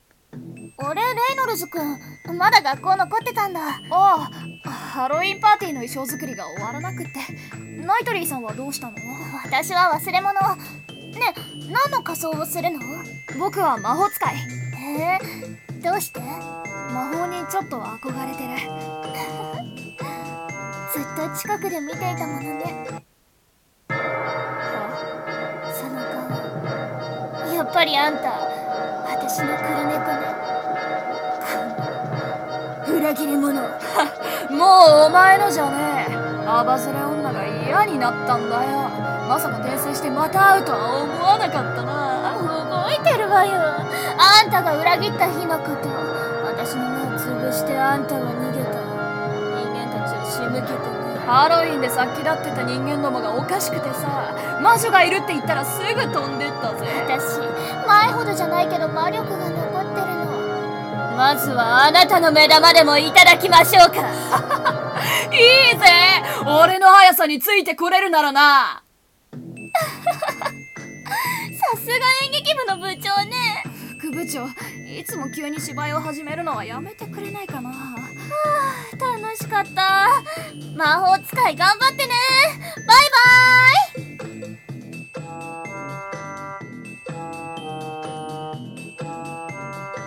Trick!Trick‼Trick!!!【2人声劇】